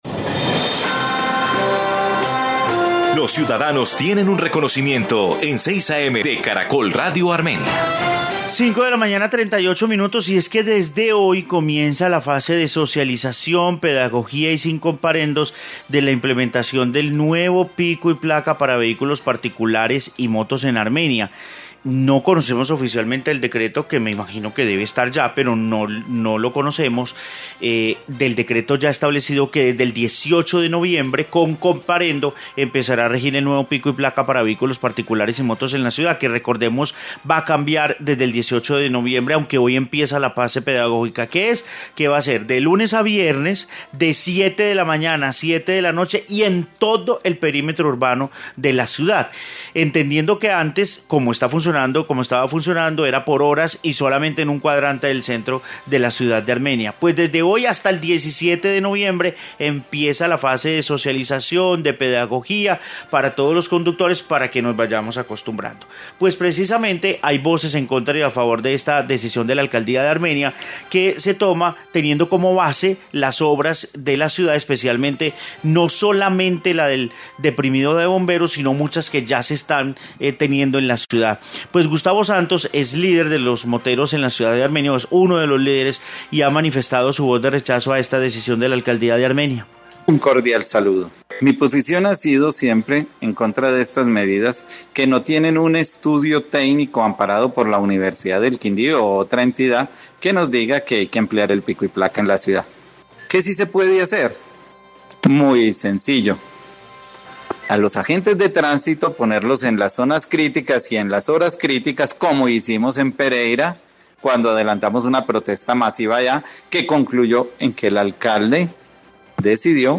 Informe Pico y Placa en Armenia